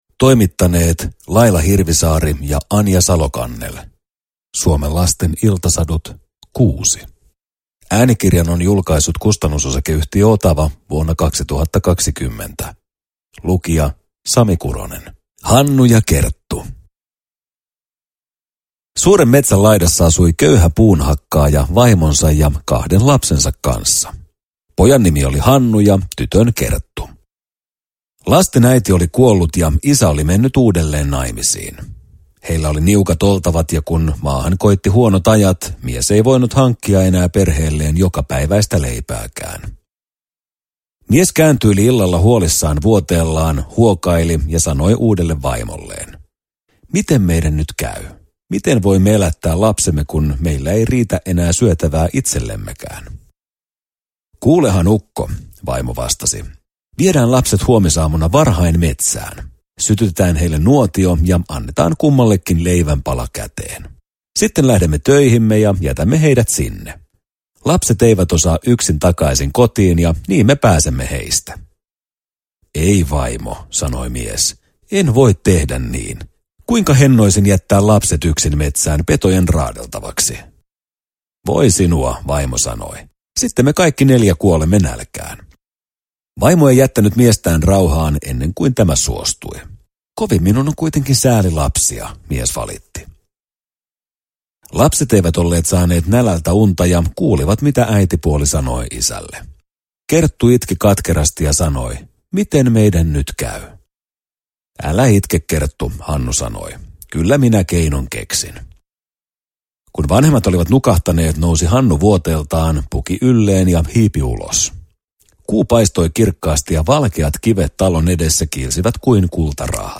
Suomen lasten iltasadut 6 – Ljudbok – Laddas ner